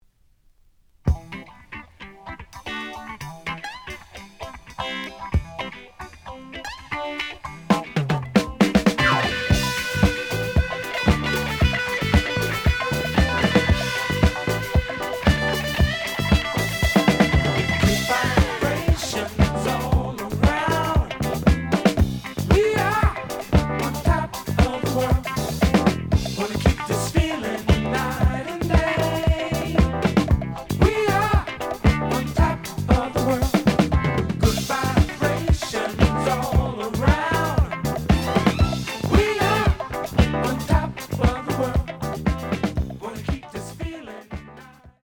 The audio sample is recorded from the actual item.
●Genre: Funk, 70's Funk
Edge warp. But doesn't affect playing. Plays good.)